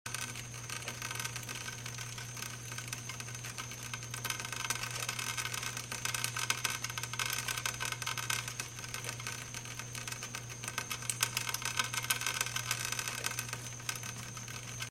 Enjoy the sounds of water sound effects free download
Enjoy the sounds of water dripping down a drainpipe.